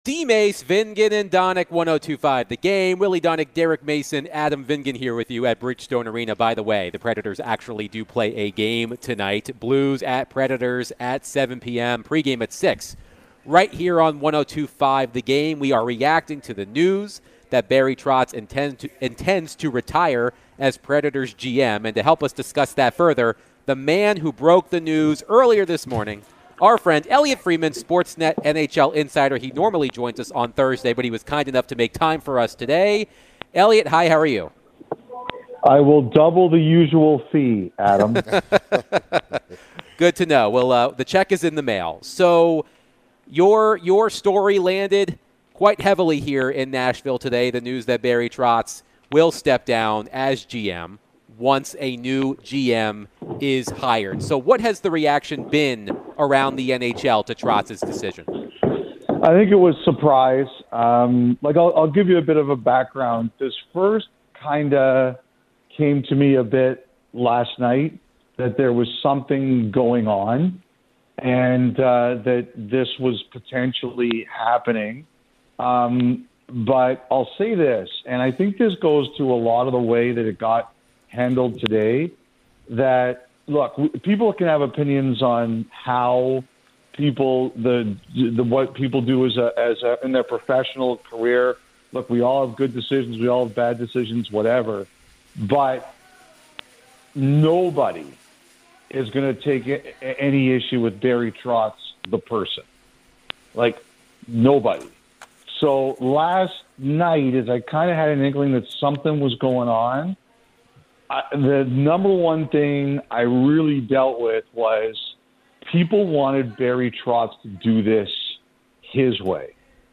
NHL Insider Elliotte Friedman joined DVD to discuss all things Barry Trotz stepping down and retiring and his story on it